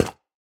Minecraft Version Minecraft Version 1.21.5 Latest Release | Latest Snapshot 1.21.5 / assets / minecraft / sounds / block / decorated_pot / step2.ogg Compare With Compare With Latest Release | Latest Snapshot